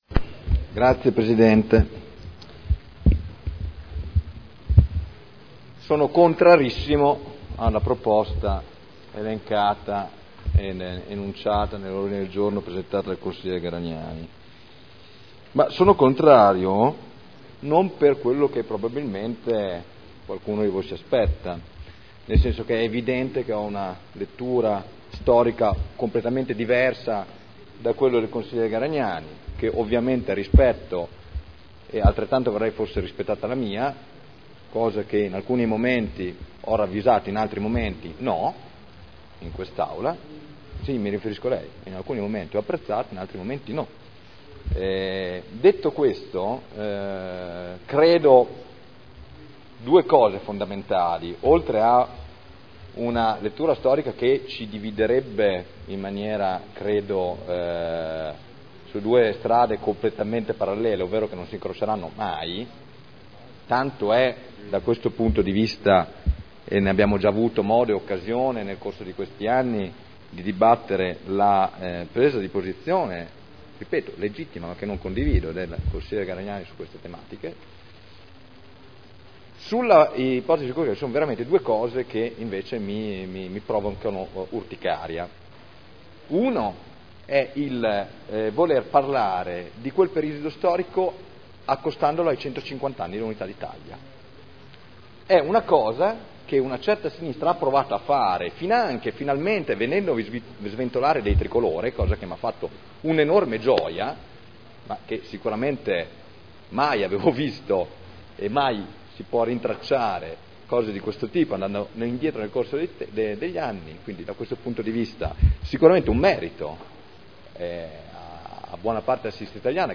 Seduta del 12/09/2011. Dibattito su Ordine del Giorno presentato dai consiglieri Garagnani, Trande, Morini, Rocco, Rimini, Cornia, Codeluppi, Prampolini, Glorioso, Goldoni, Dori, Guerzoni, Pini, Cotrino, Rossi F. (P.D.), Ricci (Sinistra per Modena) avente per oggetto: "Nuova cartellonistica per annunciare la città di Modena: MODENA Città medaglia d’oro al Valor Militare della Resistenza"